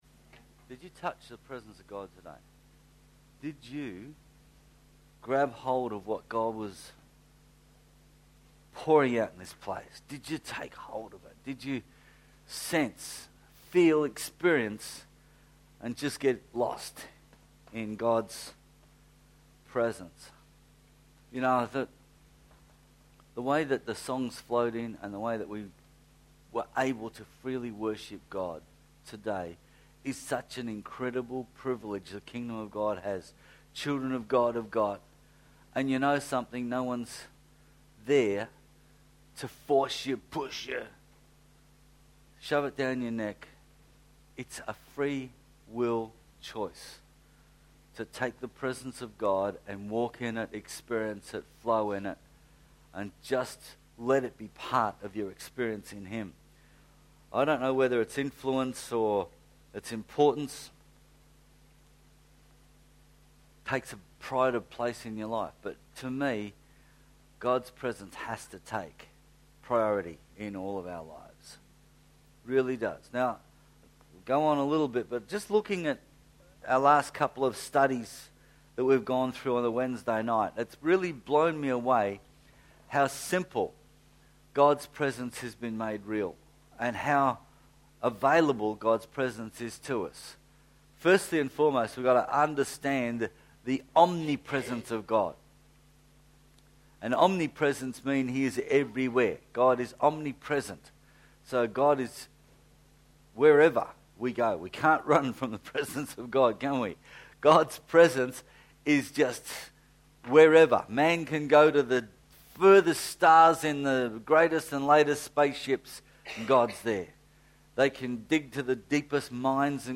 Sermon-13-4-14.mp3